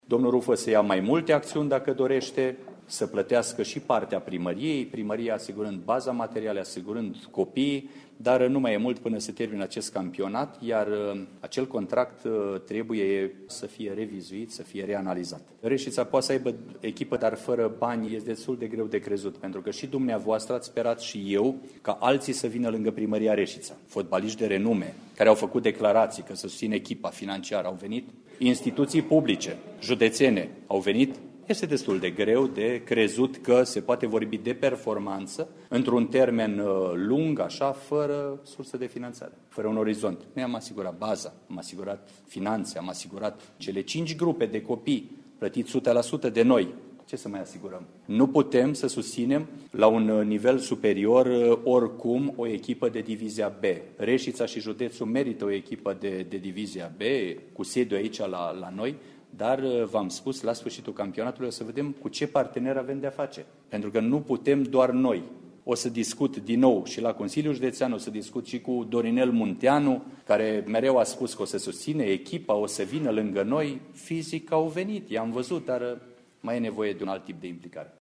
„Este destul de greu de crezut că se poate vorbi de performanţă pe termen lung fără surse de finanţare. Noi am asigurat baza, am asigurat cele cinci grupe de copii, plătite sută la sută de noi. Nu putem să susținem la un nivel superior, oricum, o echipă de Divizia B. Reșița și județul merită o echipă de Divizia B cu sediul aici la noi, dar la sfârşitul campionatului vom vedea cu ce partener avem de-a face, pentru că nu putem doar noi”, a declarat primarul Mihai Stepanescu la finalul ședinței Consiliului Local Reşiţa, care a avut loc marţi, 28 aprilie.
Ascultaţi aici declaraţia completă a primarului Mihai Stepanescu: